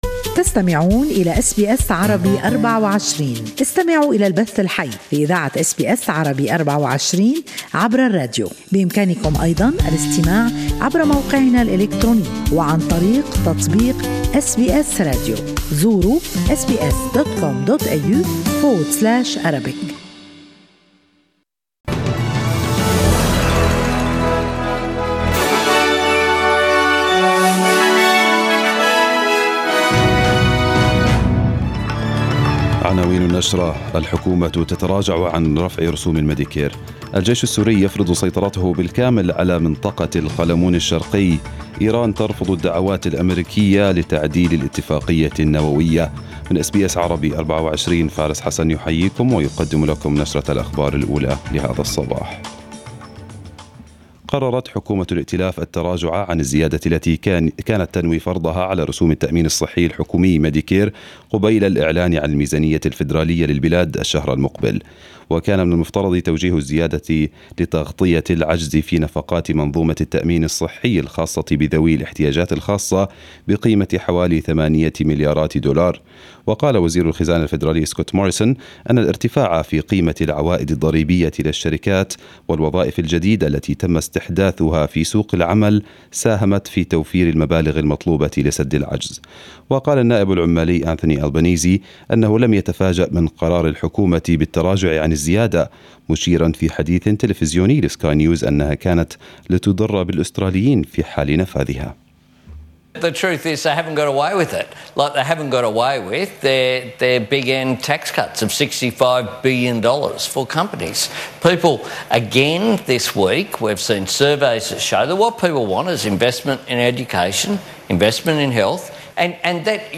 Arabic News Bulletin